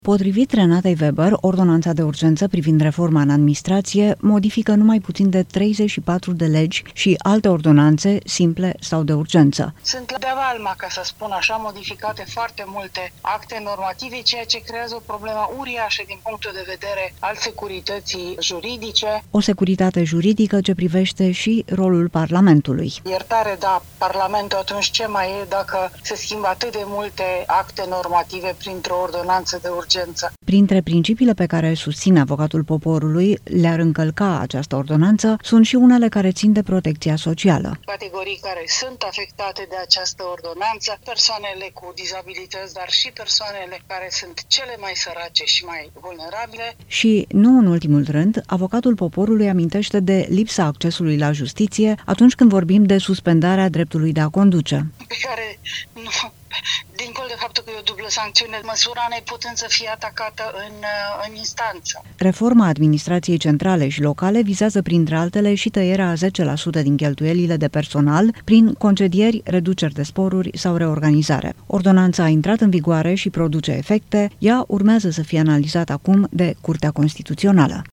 Renate Weber, care conduce Avocatul Poporului din 2019, spune la Europa FM că ordonanța de urgență încalcă de-a valma articole din Constituție și duce la restrângerea mai multor drepturi și libertăți.